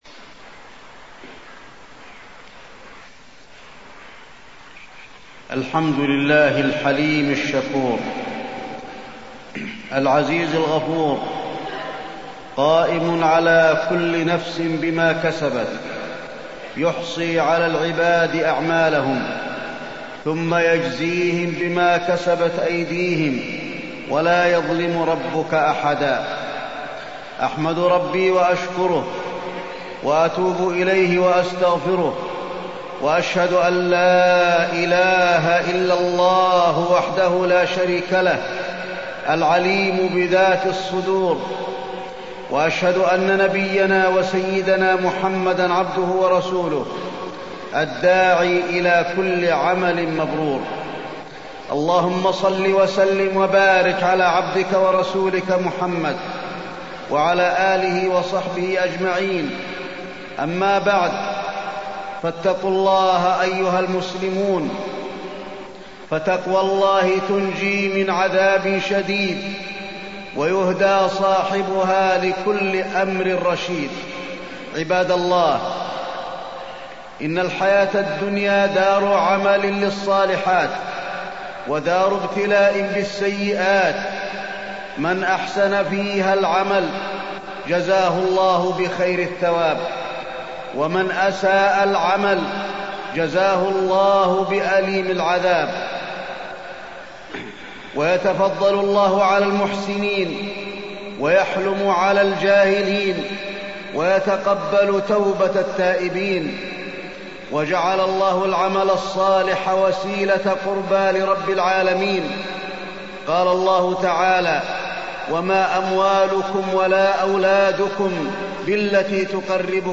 تاريخ النشر ١١ شوال ١٤٢٤ هـ المكان: المسجد النبوي الشيخ: فضيلة الشيخ د. علي بن عبدالرحمن الحذيفي فضيلة الشيخ د. علي بن عبدالرحمن الحذيفي العمل الصالح